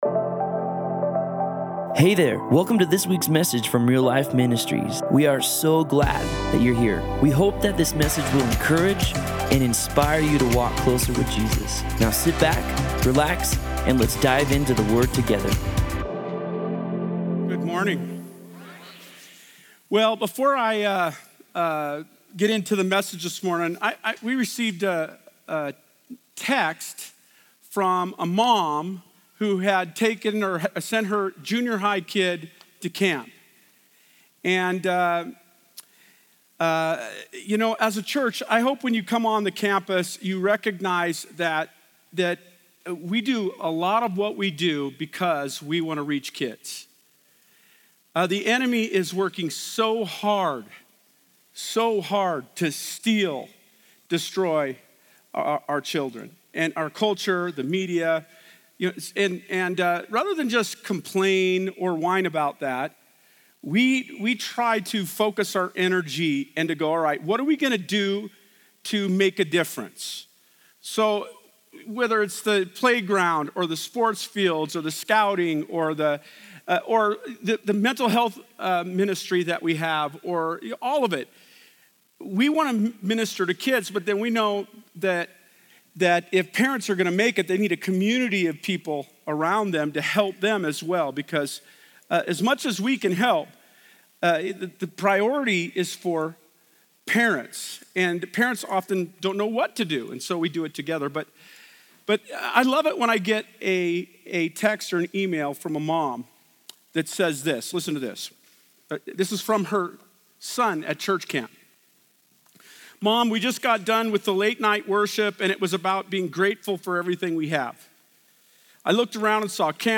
Other Sermon